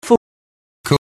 We can hear in that last clip how the backed GOOSE vowel in fool is similar in quality to the close-ish THOUGHT vowel of contemporary fall. Here are the beginnings of Collins’ fool and Sheeran’s course:
fool_collins_course_sheeran.mp3